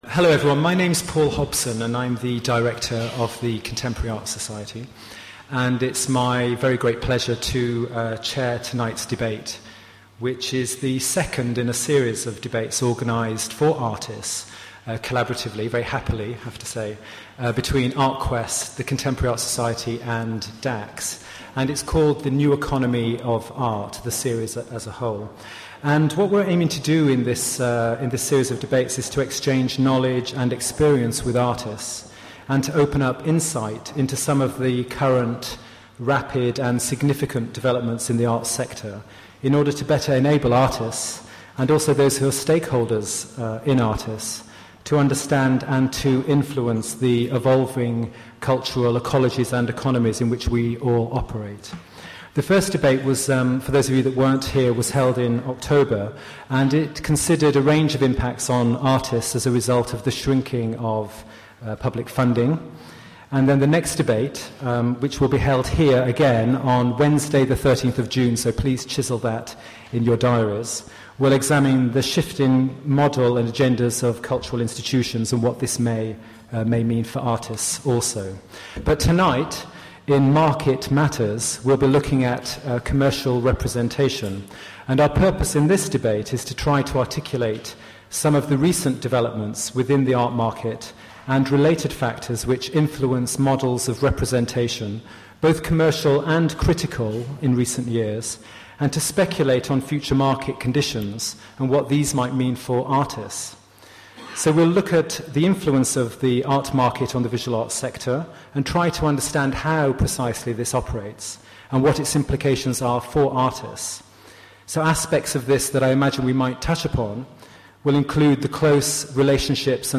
Where: London
This debate is part of The New Economy of Art – a series of open discussions that focus on the economic developments and opportunities in the cultural sector that impact on artists, from the perspective of artists.